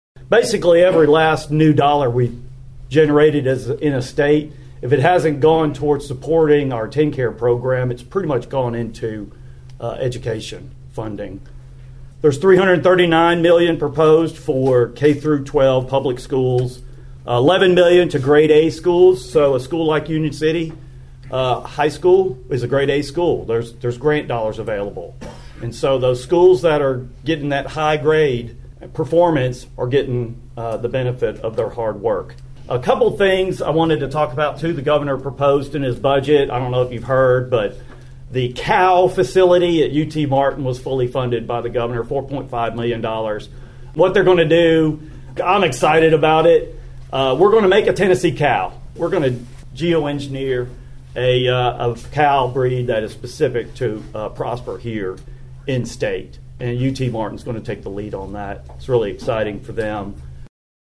The lawmakers took part in the annual Obion County Farm Bureau Legislative Coffee.
Senator Stevens began his appearance with a stern comment concern the recent law enforcement shooting in Weakley County.(AUDIO)